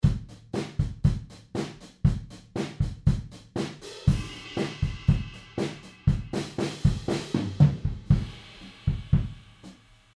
Room Mics: